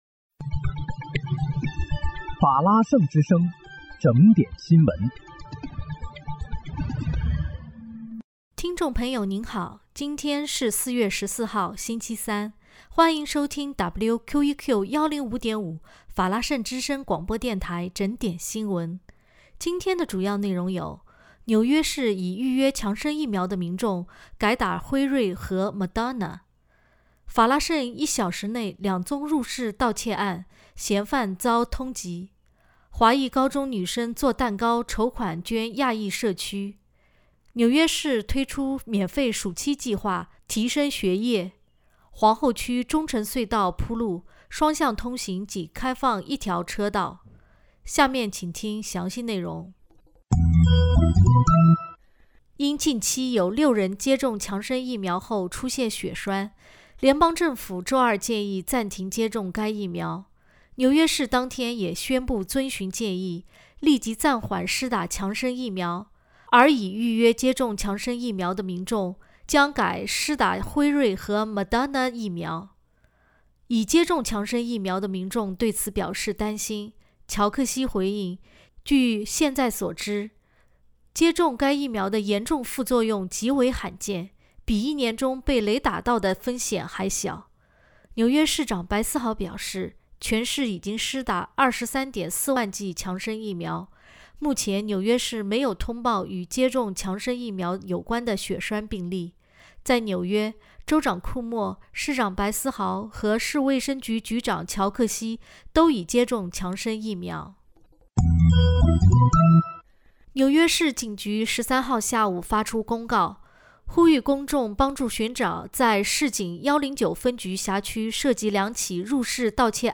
4月14日（星期三）纽约整点新闻